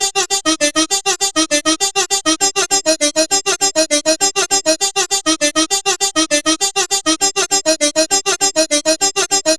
From soulful vocal chops and hypnotic melodies to groovy basslines and punchy drum loops, each sample captures the essence of Ritviz’s unique style.
Gully-Loops-Habibi-Drop-Loop-BPM-100-G-Min.wav